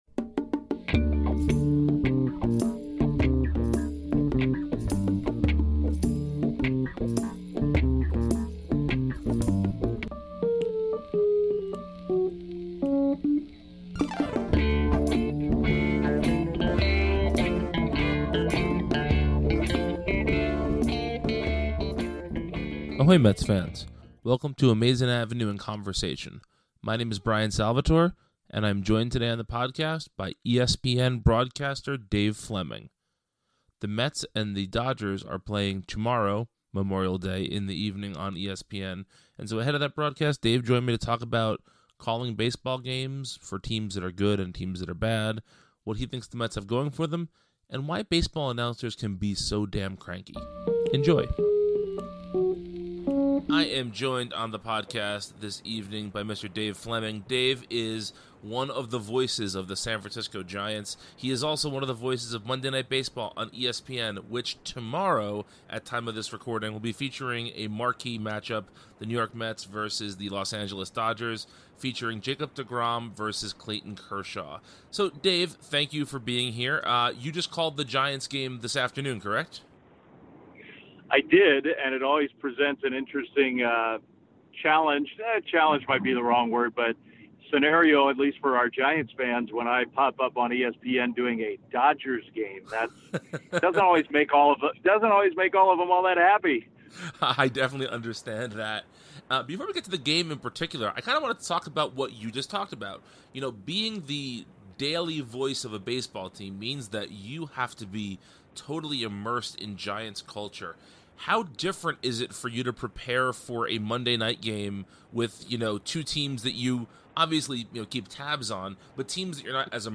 Welcome to Amazin’ Avenue in Conversation, a new podcast from Amazin’ Avenue where we invite interesting people on the show to talk about themselves, the Mets, and more.
Today, we welcome San Francisco Giants and ESPN broadcaster Dave Flemming to the show.